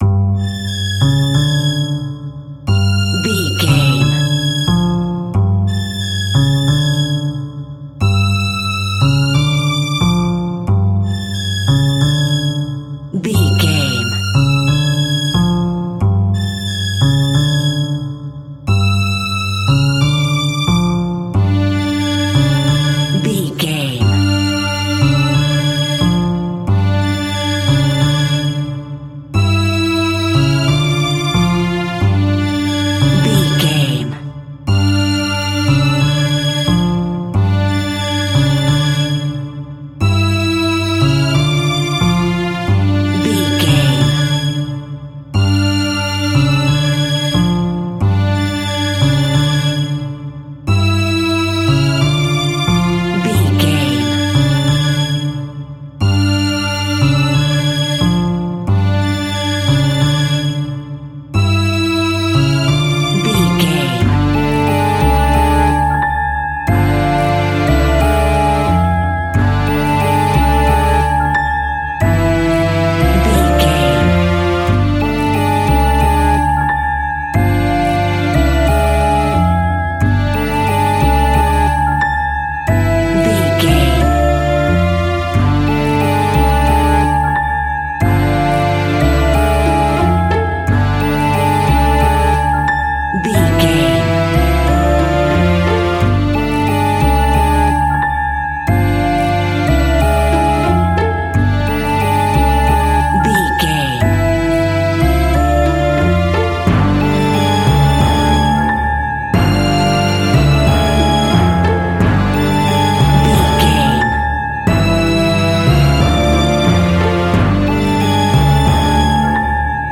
Diminished
scary
ominous
dark
haunting
eerie
strings
piano
electric piano
horror
tense
Horror Synths
Scary Strings